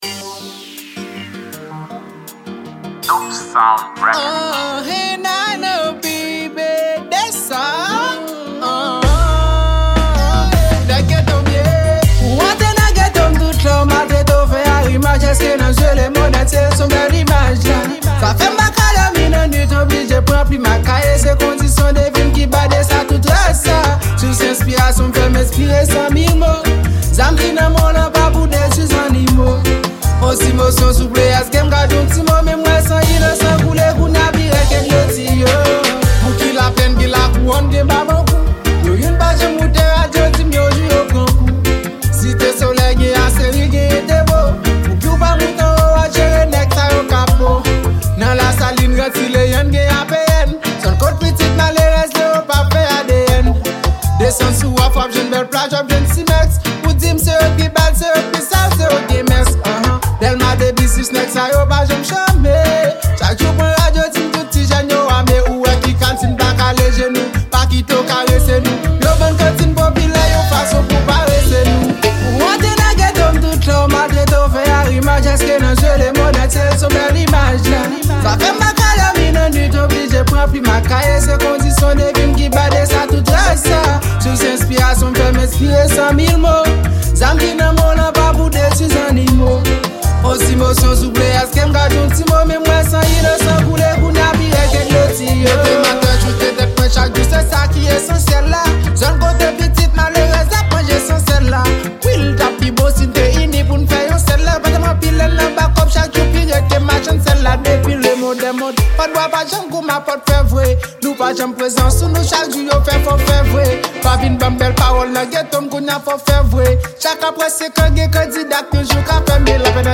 Genre: Rap & Reggae.